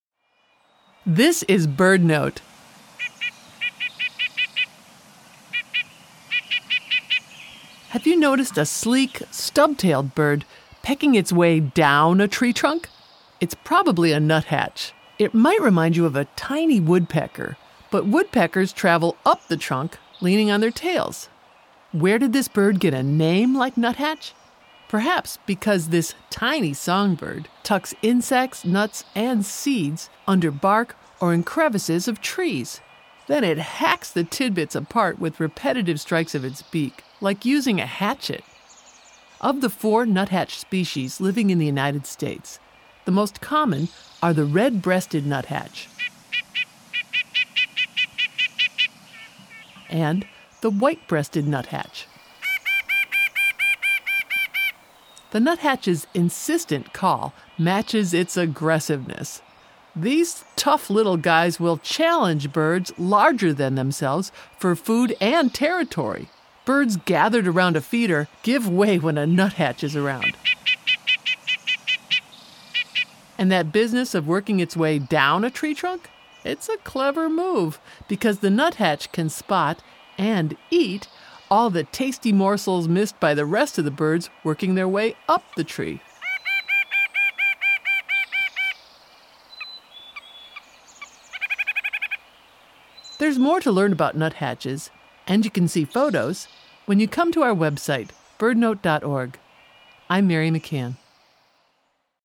The nuthatch’s insistent call matches its aggressiveness. As they work their way down a tree trunk, nuthatches can spot — and eat — all the tasty morsels missed by the rest of the birds working their way up the tree.